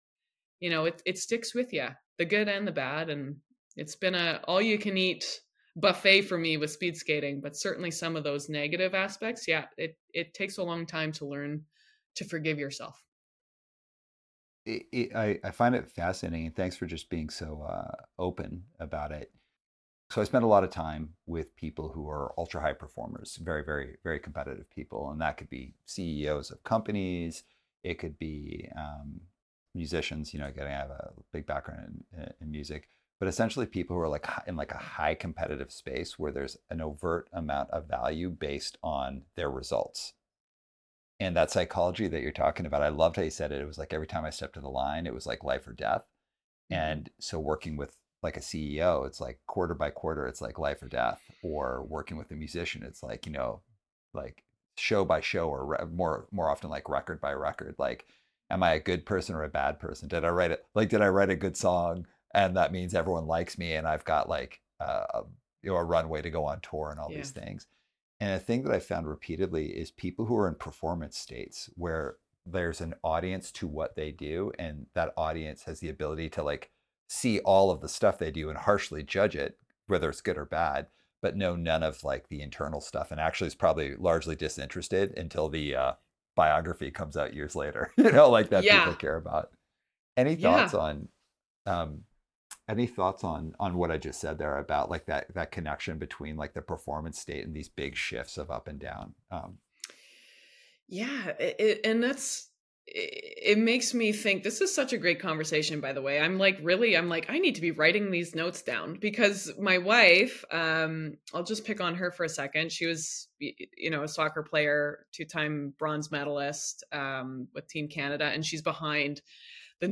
On this episode of One Step Beyond, we are joined by Anastasia Bucsis, two-time Olympic speed skater and host, CBC Sports.
In this conversation